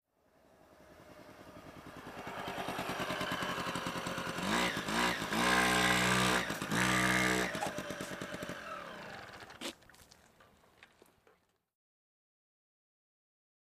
Motor Scooter, In Medium Speed, Stop Cu, Rev, Off.